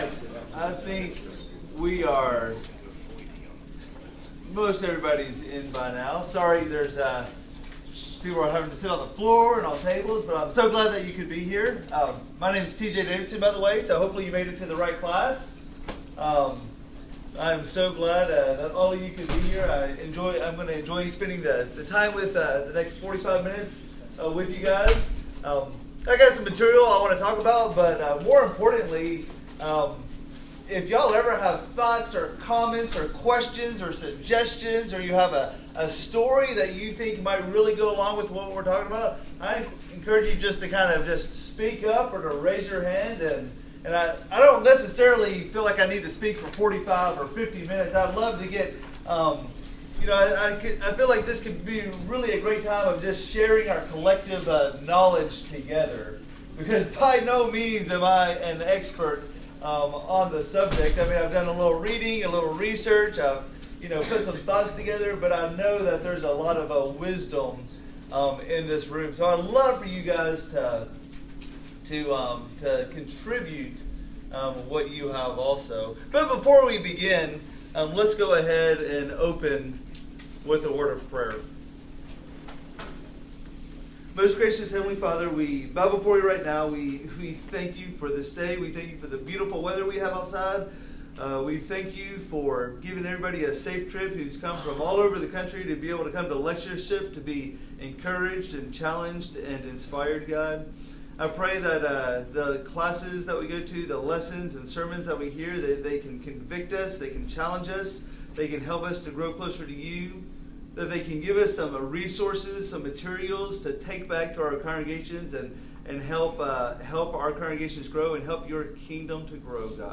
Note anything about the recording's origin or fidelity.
Scholar Works at Harding - Annual Bible Lectureship: Sticky Faith: Helping Teens To Stay Faithful For the Long Haul